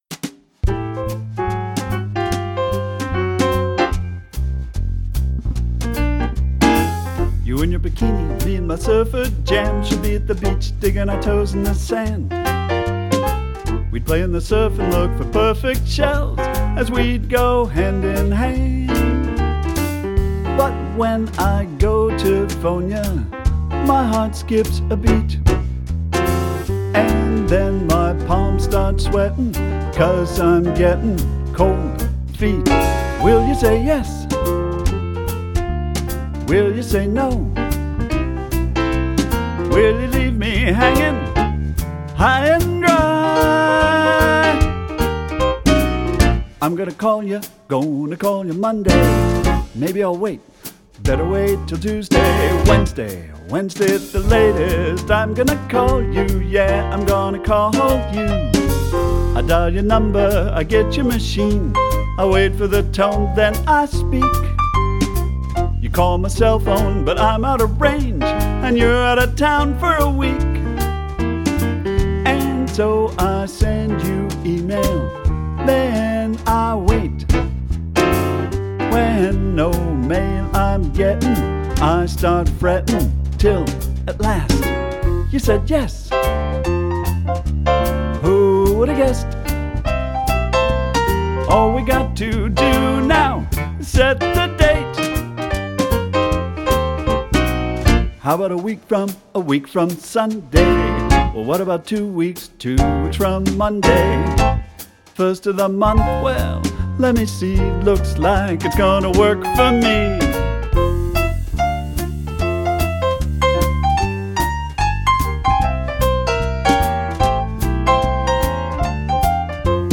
The singers perform with a jazz trio.